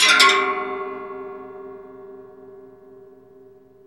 METAL HIT 6.wav